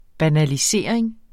Udtale [ banaliˈseˀɐ̯eŋ ]